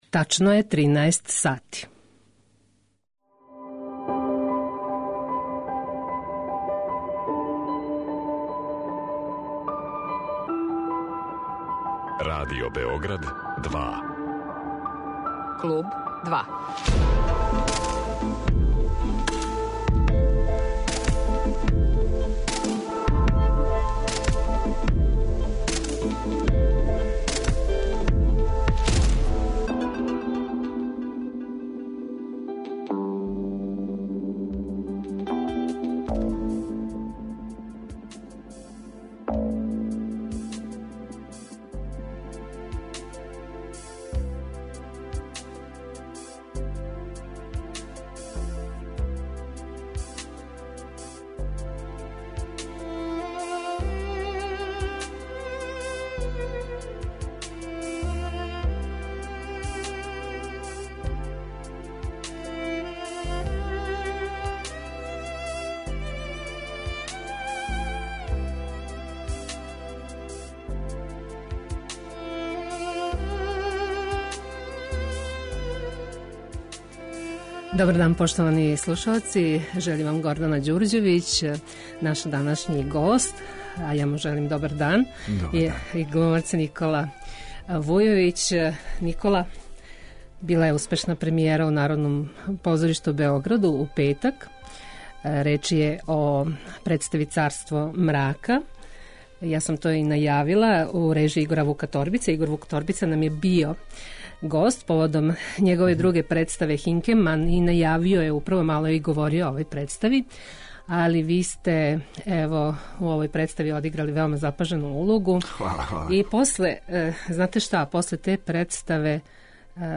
Гост је глумац